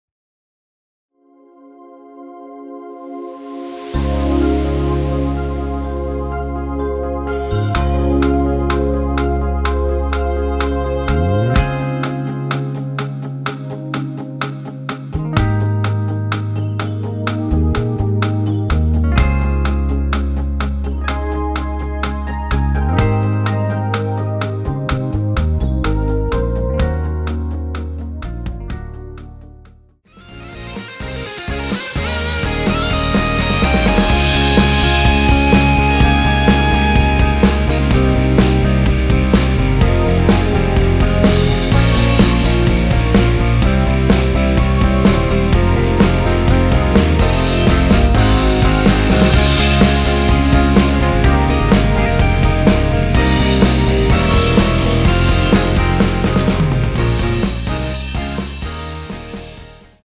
원키에서(-1)내린 MR입니다.
Ab
앞부분30초, 뒷부분30초씩 편집해서 올려 드리고 있습니다.
중간에 음이 끈어지고 다시 나오는 이유는